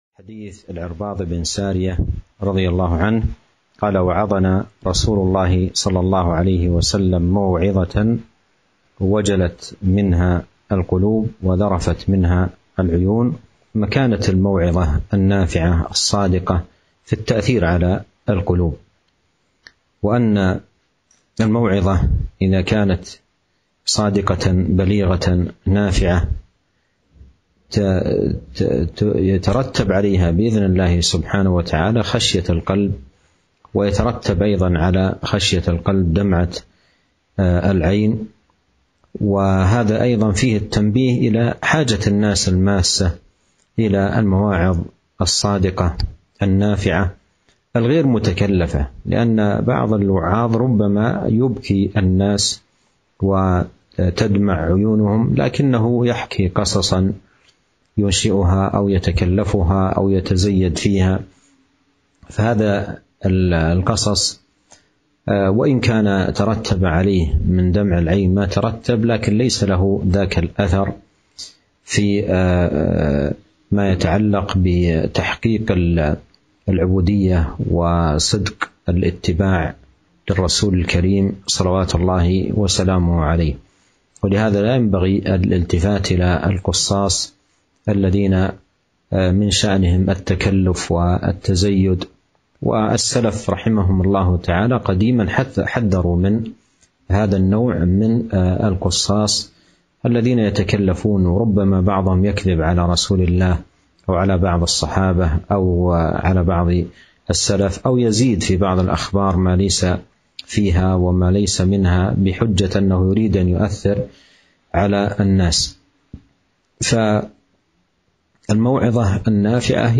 شرح حديث وعظنا رسول الله ﷺ موعظة وجلت منها القلوب وذرفت منها العيون